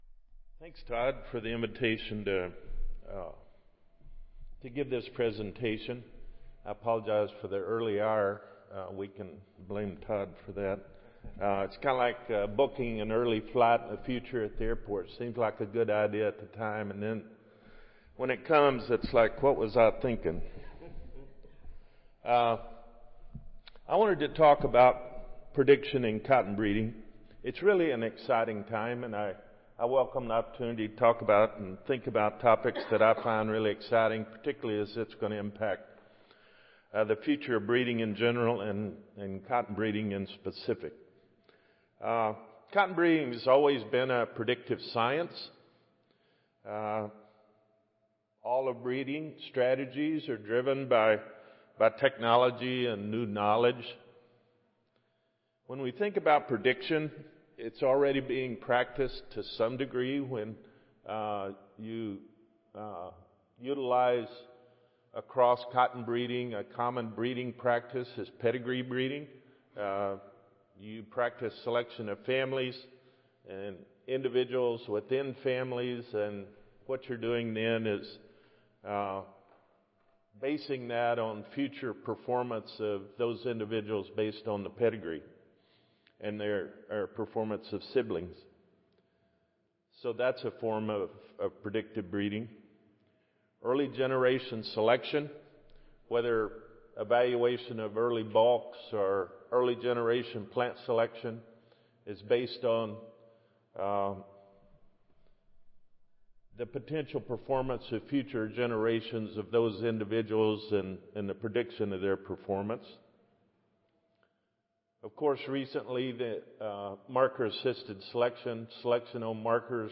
Salon I (Marriott Rivercenter Hotel)
Recorded Presentation